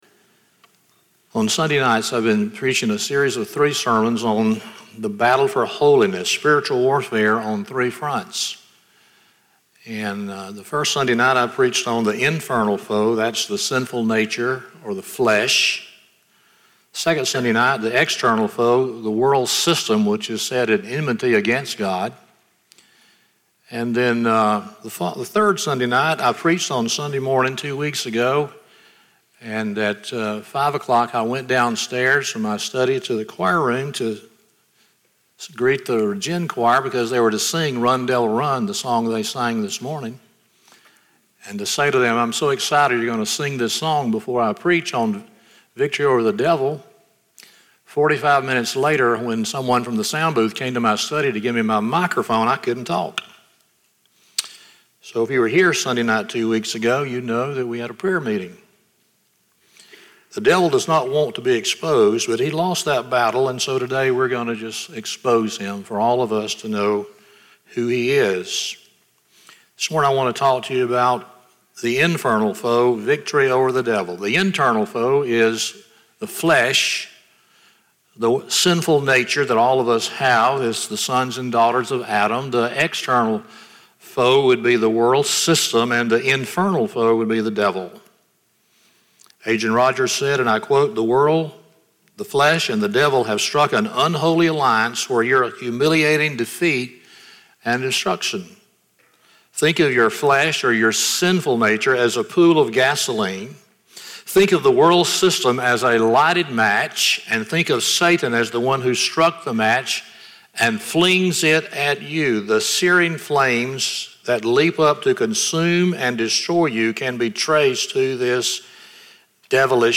James 4:7-10 Service Type: Sunday Morning 4 Sobering Facts About The Devil